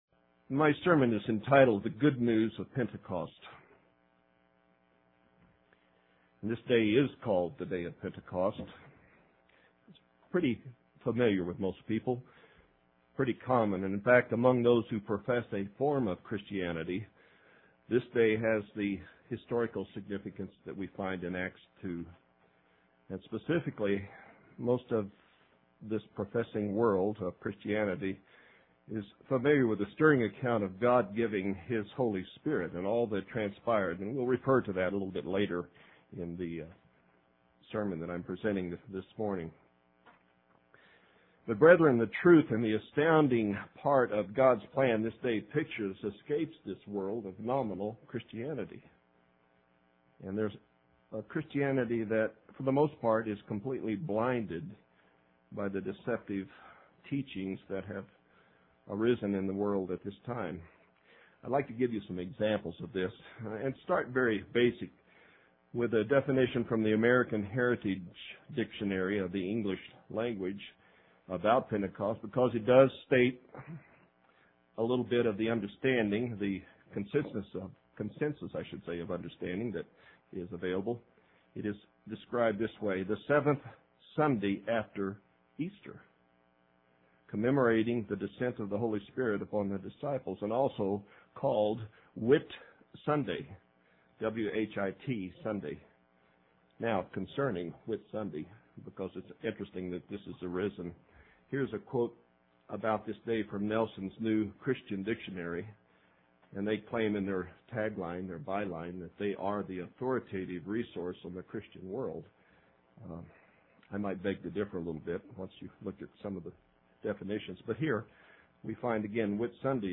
Sermons – Page 223 – Church of the Eternal God